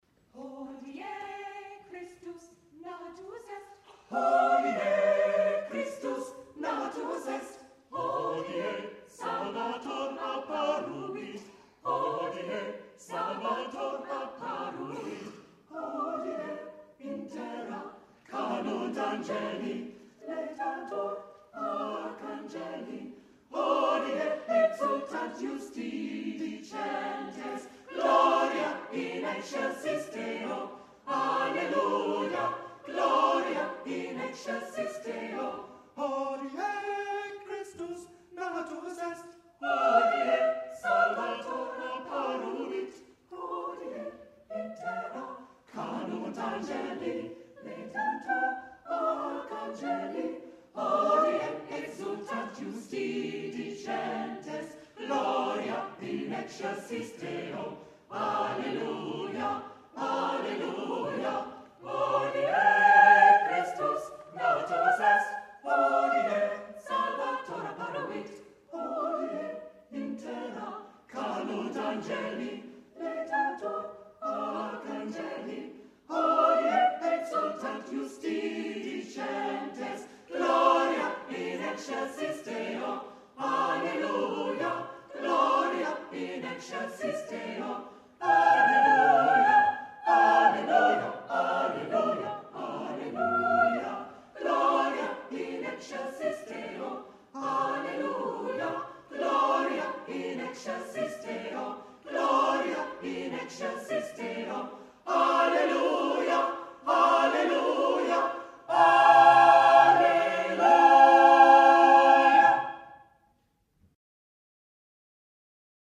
New Dublin Voices and other choral singing, what I'm up to
Here’s a recording, too, of our performance of Poulenc’s Hodie Christus natus est in the final: